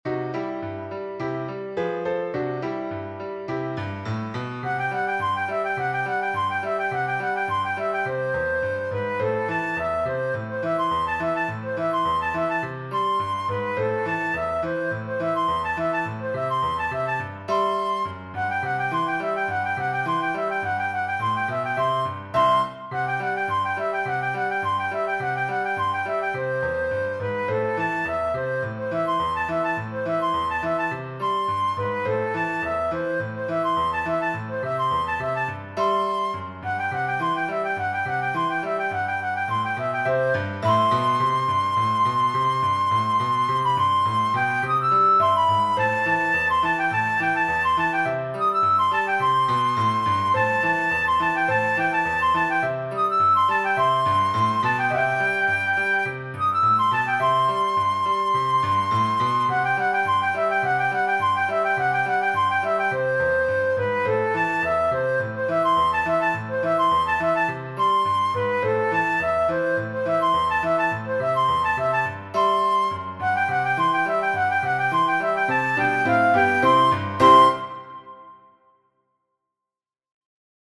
This arrangement is for flute and piano.